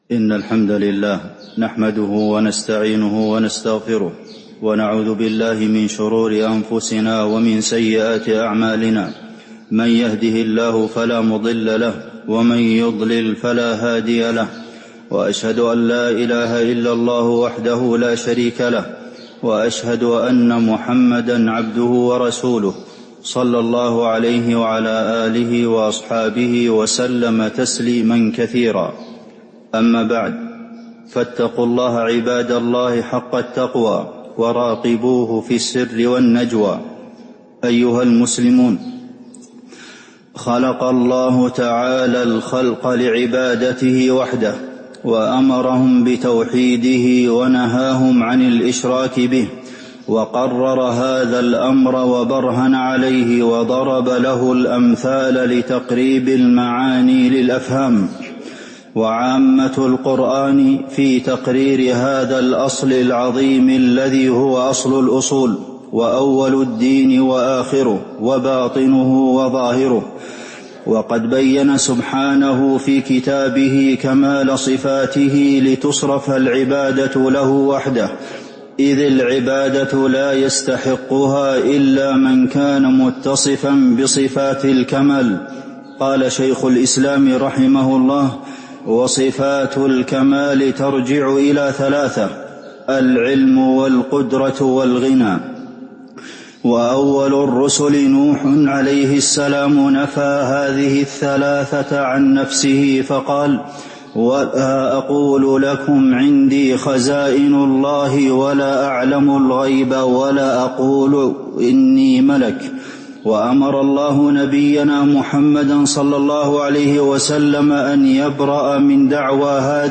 تاريخ النشر ١ شعبان ١٤٤٦ هـ المكان: المسجد النبوي الشيخ: فضيلة الشيخ د. عبدالمحسن بن محمد القاسم فضيلة الشيخ د. عبدالمحسن بن محمد القاسم الله وحده المستحق للعبادة